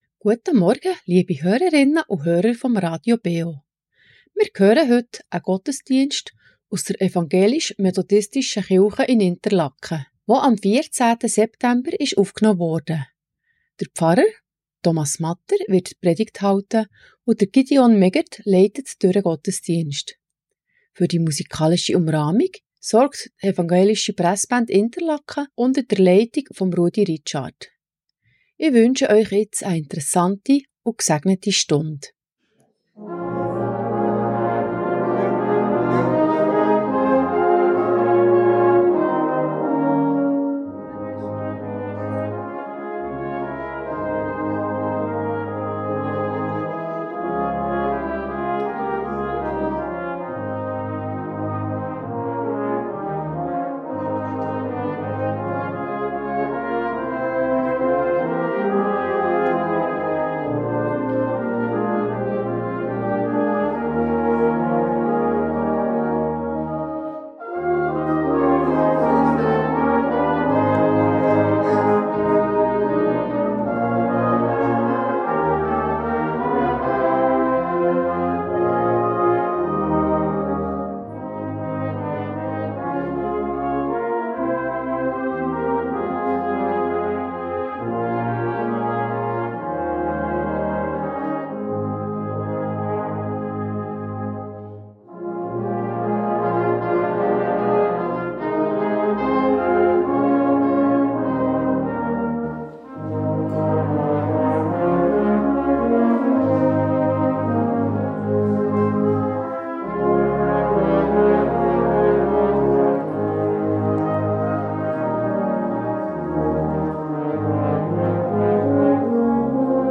Evangelisch-methodistische Kirche Interlaken ~ Gottesdienst auf Radio BeO Podcast